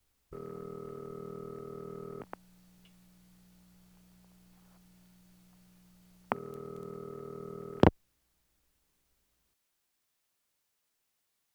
Telephone Rings And Pick Up Internal Sound Effect
Download a high-quality telephone rings and pick up internal sound effect.
telephone-rings-and-pick-up-internal.wav